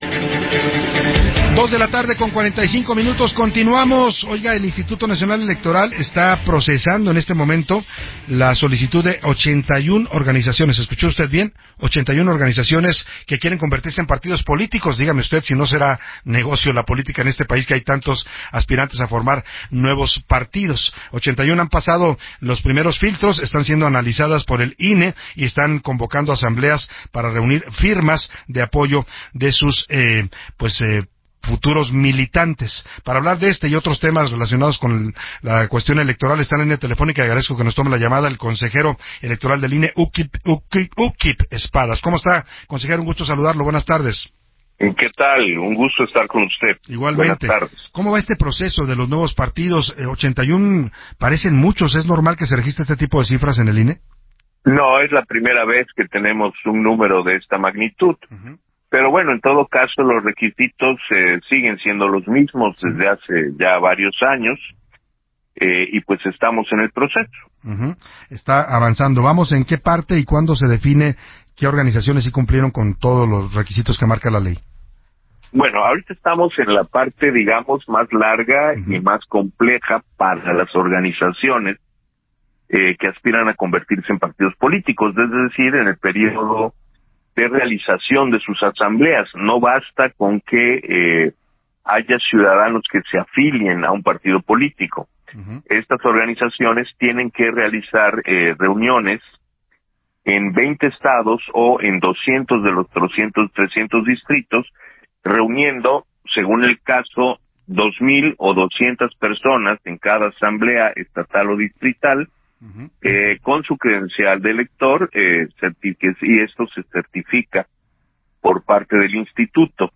Entrevista del Consejero Electoral Uuc Kib Espadas con Salvador García Soto para el Heraldo Radio